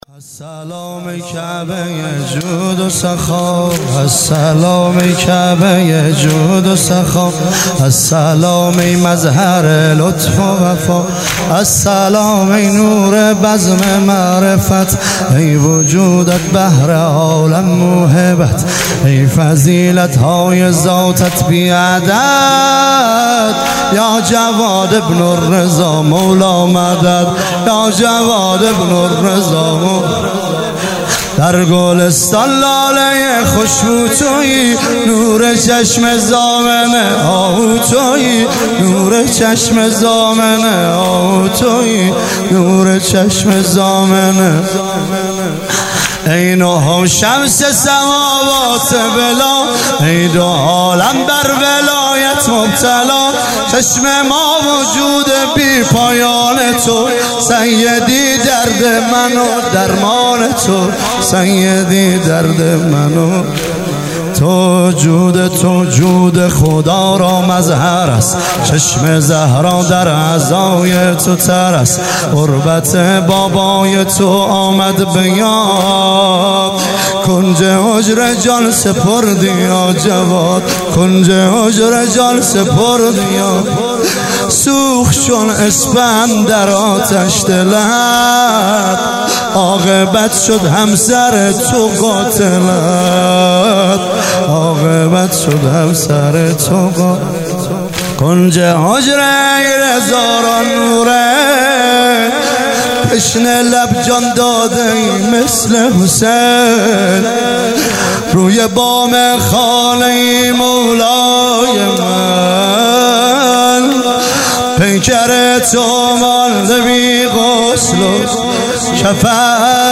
شهادت امام جواد علیه السلام
واحد مداحی